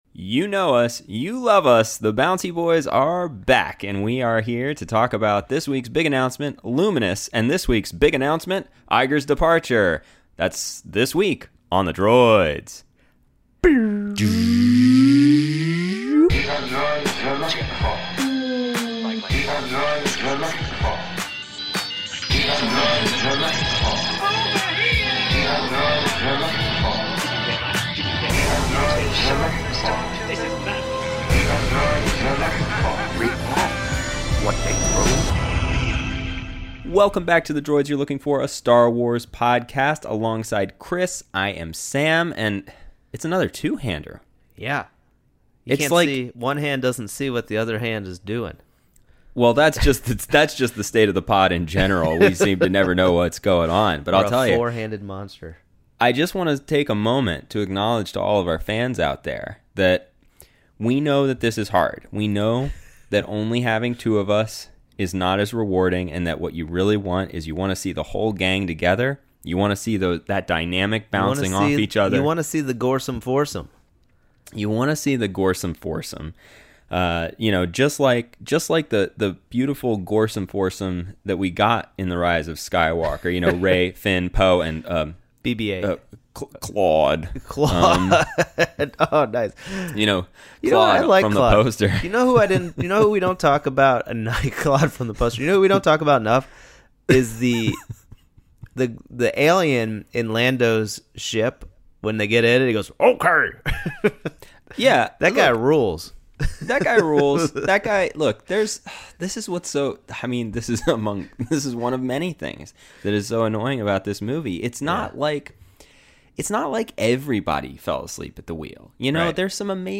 The bouncy boys talk Project Luminous (aka The High Republic), what it means for Star Wars, and where they think the future will go.